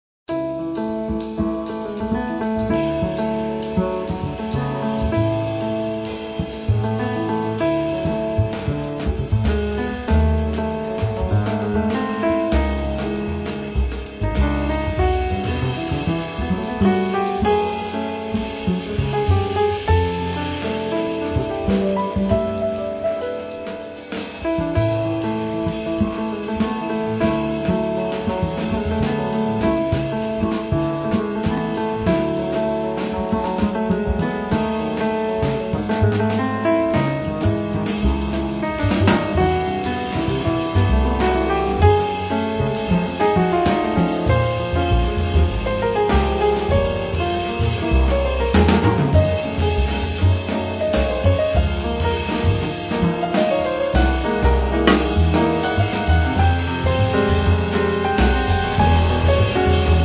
pianoforte
basso
batteria